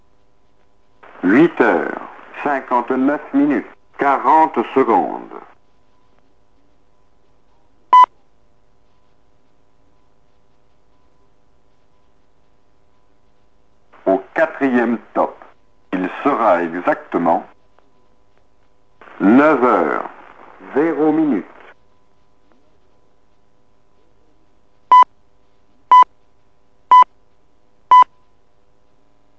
Les tops secs sont espacés d’une seconde et le zéro de chaque minute correspond au début du quatrième top.